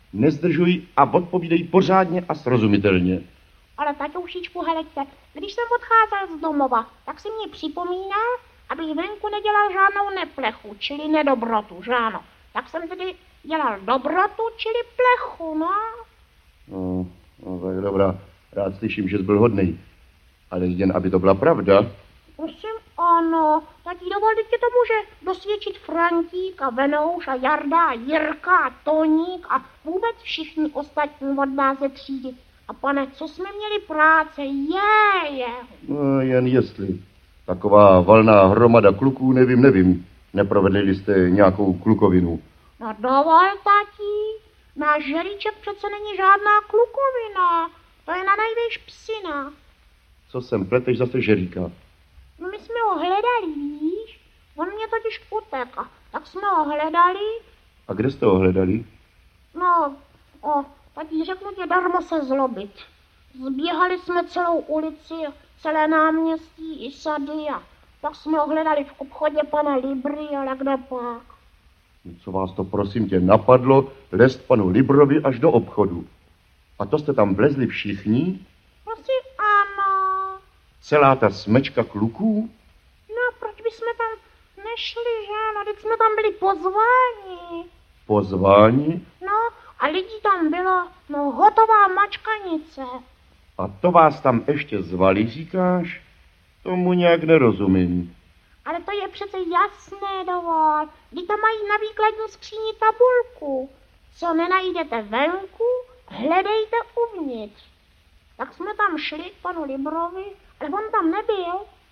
Audio kniha
Jeho loutkové postavičky si záhy získaly obrovskou popularitu a rozhovory obou hrdinů byly nahrávány na gramofonové desky, které jejich slávu dále šířily.